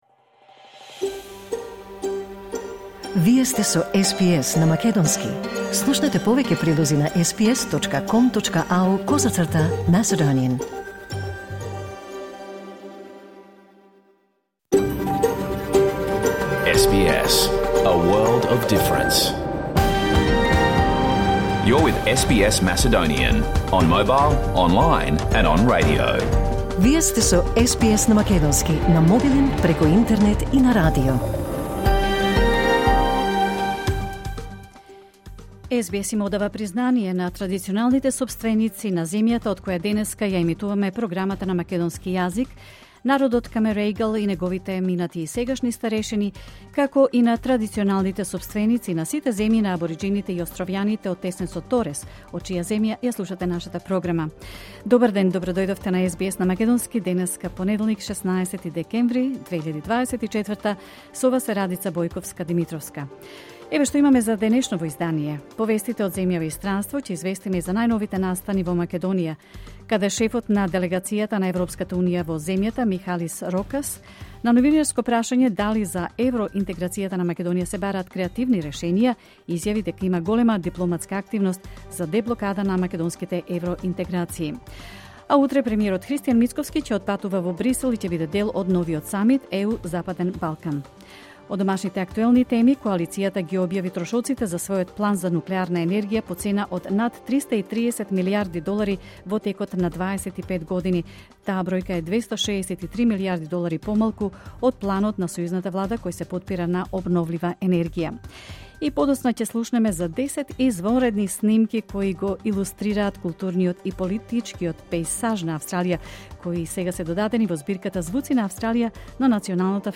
SBS Macedonian Program Live on Air 16 December 2024